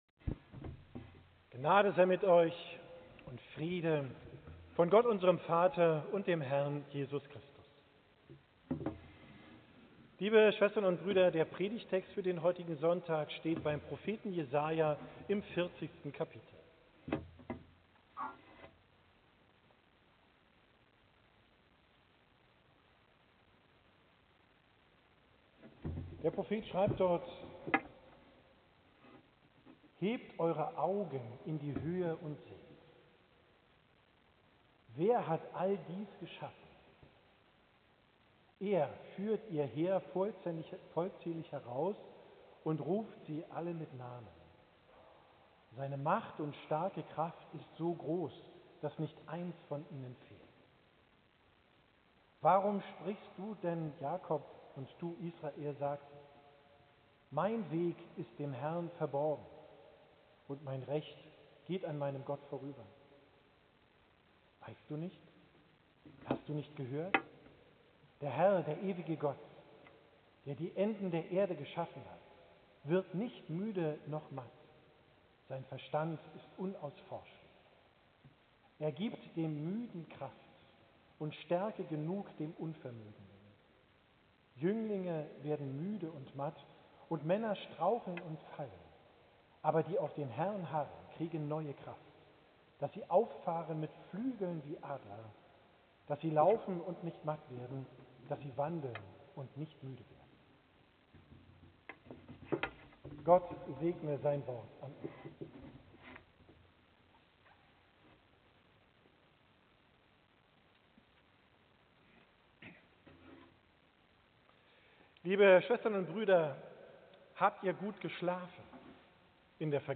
Predigt vom Sonntag Quasimodogeniti, 12.